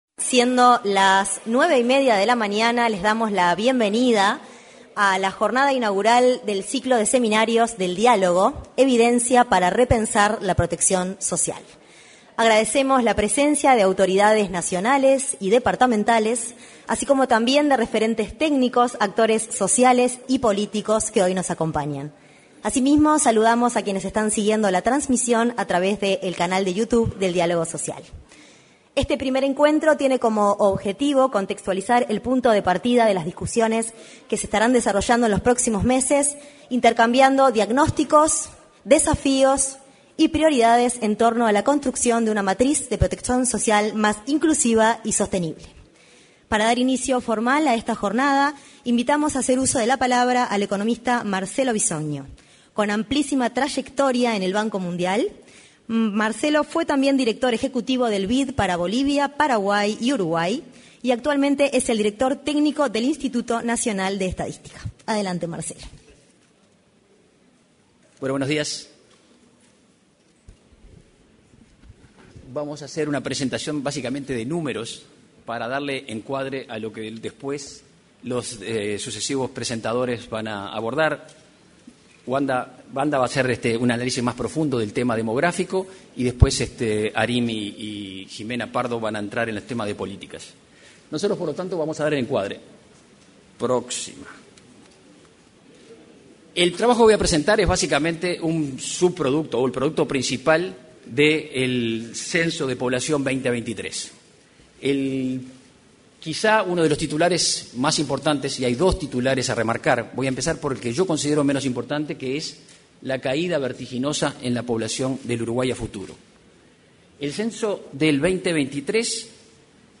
Jornada inaugural del ciclo de seminarios del Diálogo Social 31/07/2025 Compartir Facebook X Copiar enlace WhatsApp LinkedIn En el auditorio del anexo a la Torre Ejecutiva, durante la jornada inaugural del ciclo de seminarios del Diálogo Social, se expresaron el director del Instituto Nacional de Estadística, Marcelo Bisogno; el director de la Oficina de Planeamiento y Presupuesto, Rodrigo Arim, y el coordinador de la Comisión Ejecutiva del Diálogo Social, Hugo Bai.